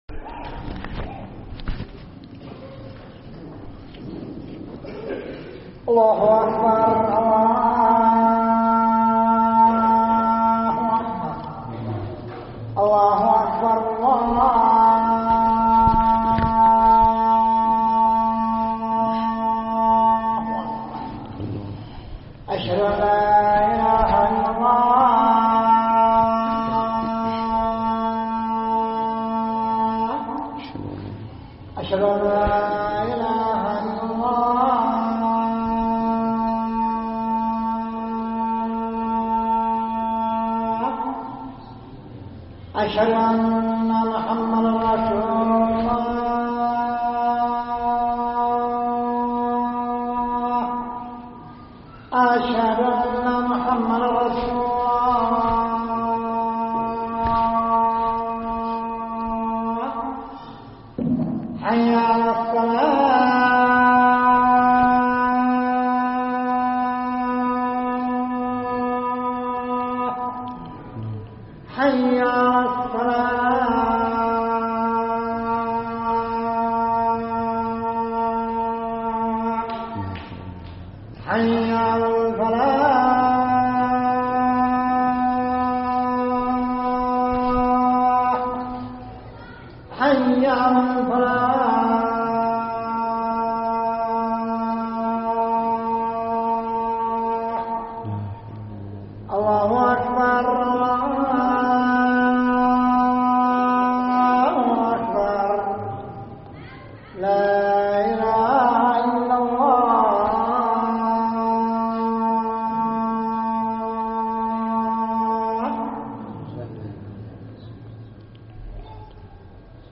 خطب الجمعة
ألقيت بدار الحديث السلفية للعلوم الشرعية بالضالع في 15 صفر 1437هــ